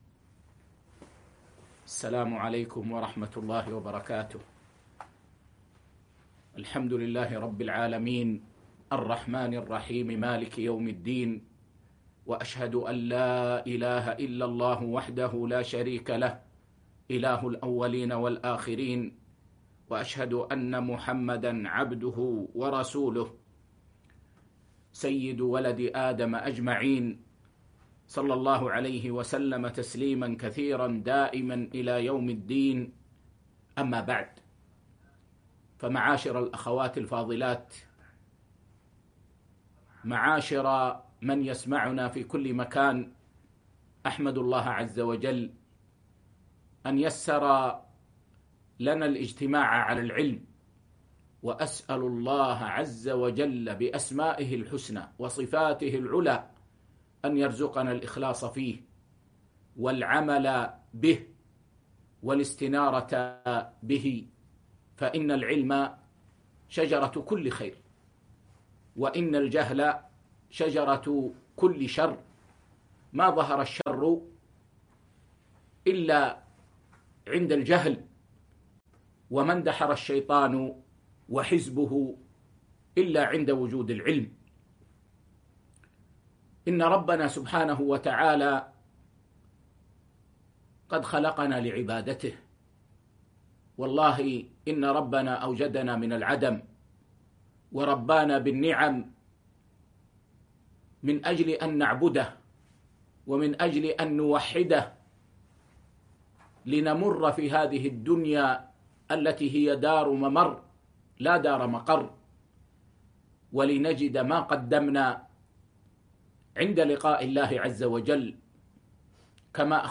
محاضرة - حجابك سر عفافك